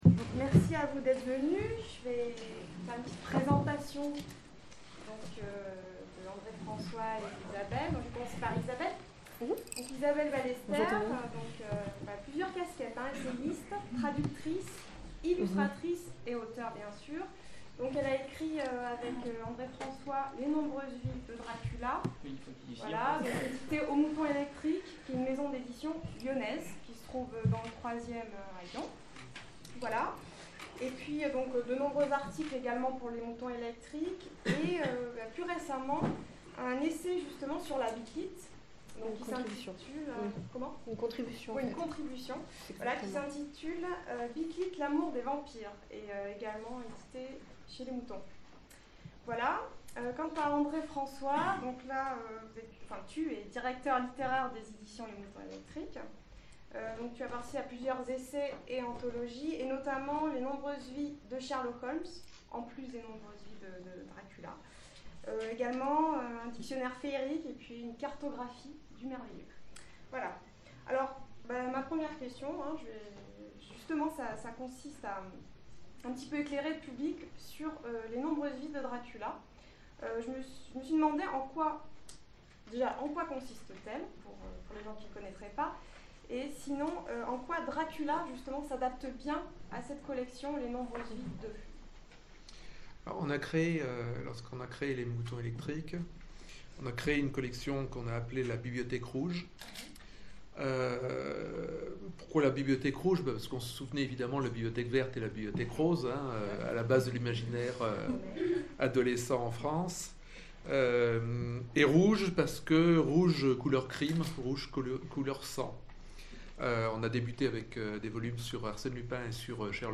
Le Salon du Vampire 2010 : Conférence Dracula et son influence sur la littérature vampire
" lors du Salon du Vampire à Lyon en décembre 2010.